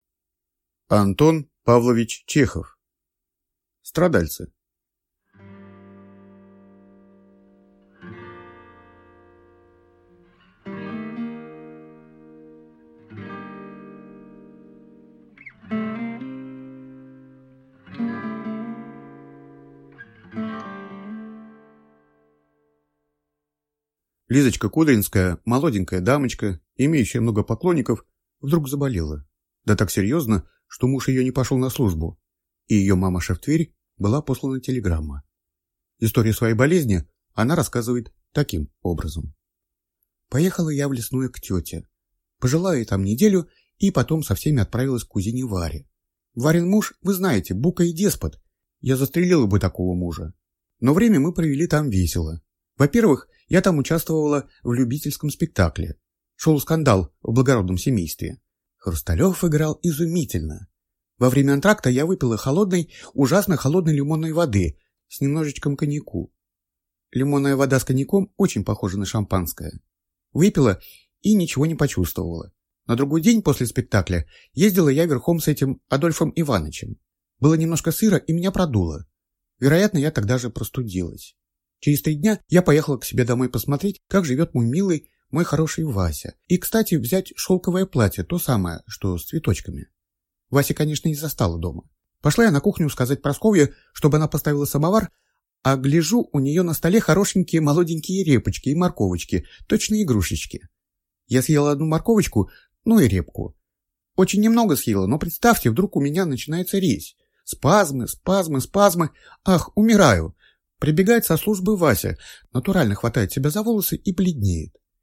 Аудиокнига Страдальцы | Библиотека аудиокниг